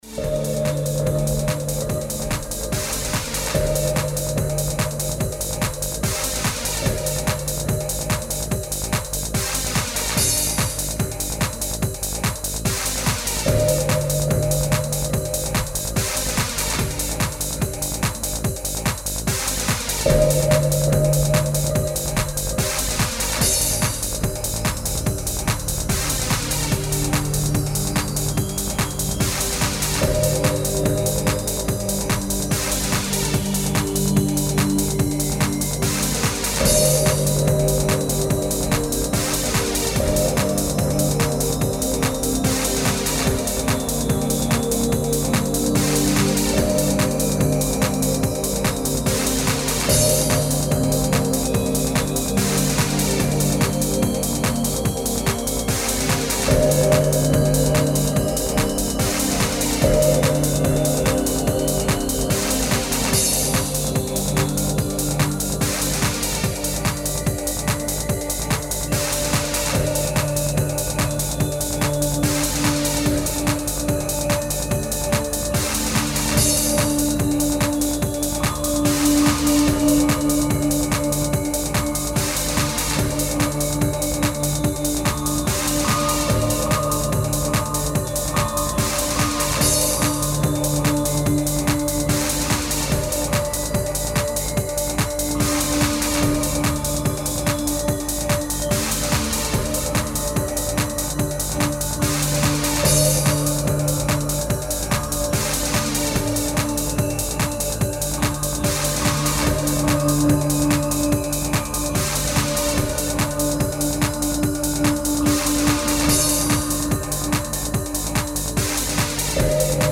Techno & Rave
Rave Disco Beats mit sphärischen Synths.